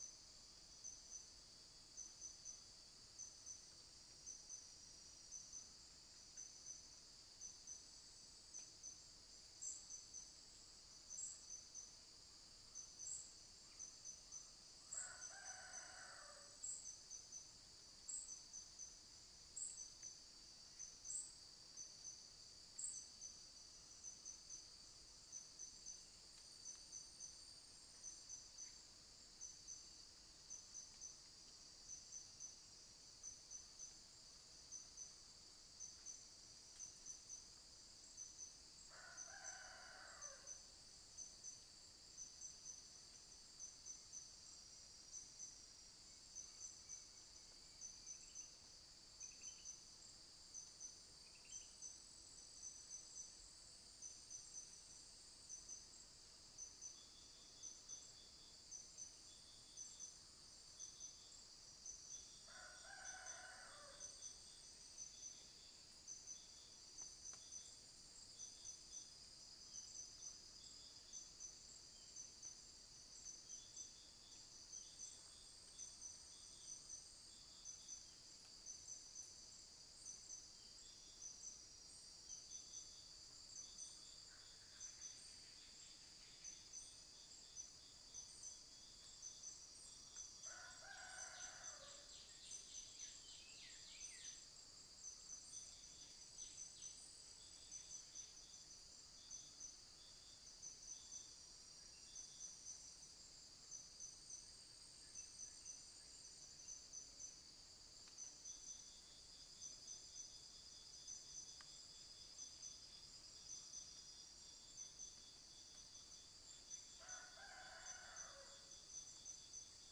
Pycnonotus erythropthalmos
unknown bird
Dicrurus paradiseus
Malacopteron magnum